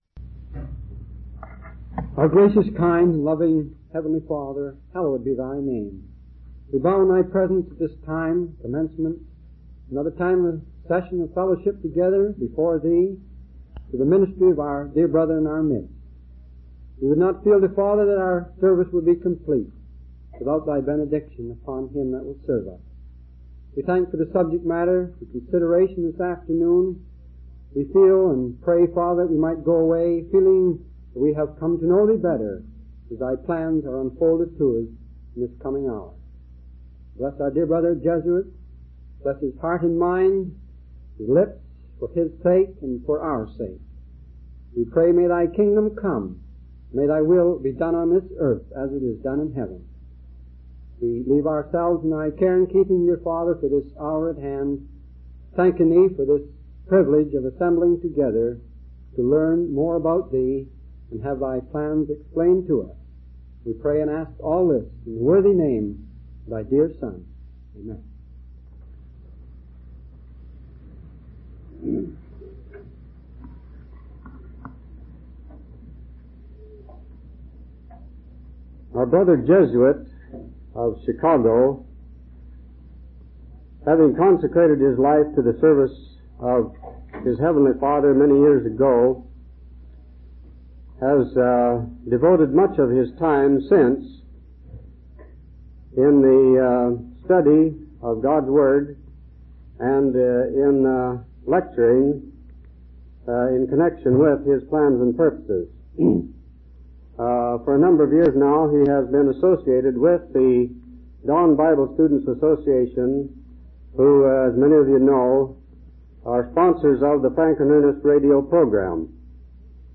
From Type: "Discourse"
Originally given in Seattle WA as an adjunct to the Seattle Convention in 1956